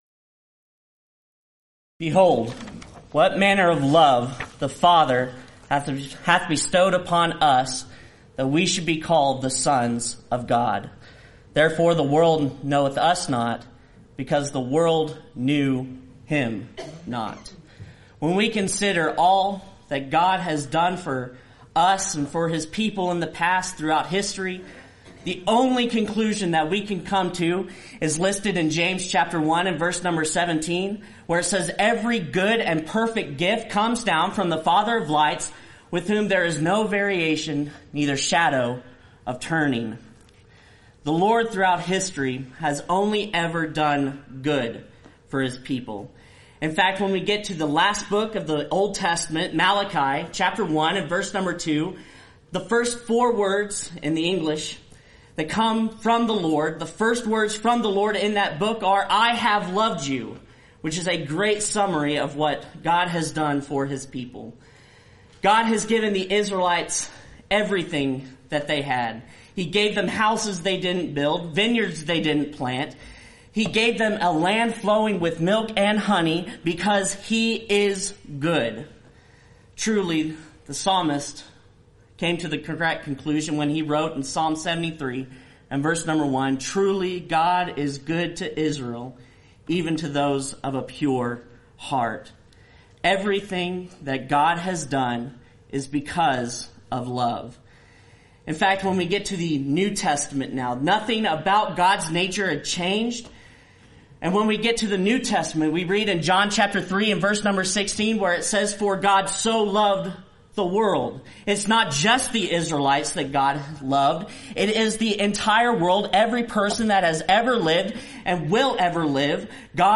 Event: 26th Annual Lubbock Lectures